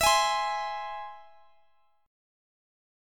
Listen to E+ strummed